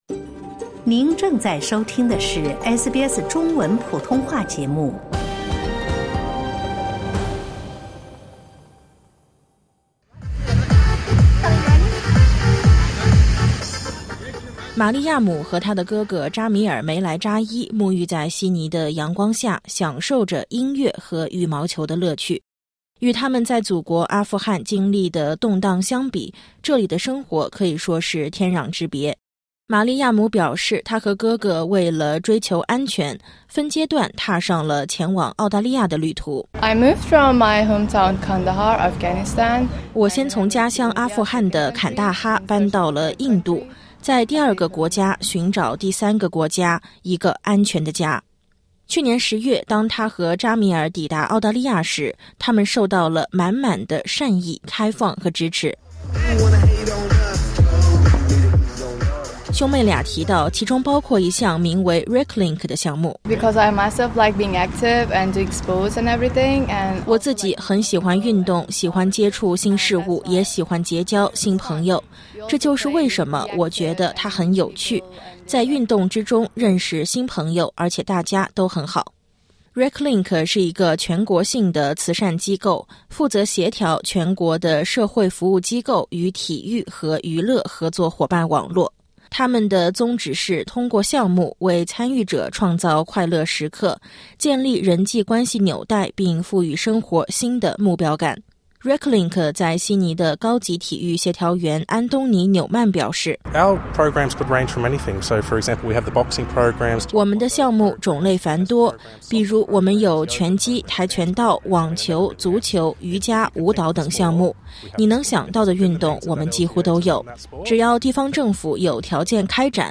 Reclink组织正在帮助最近移居澳大利亚的难民在这个新国家站稳脚跟。点击音频，收听完整报道。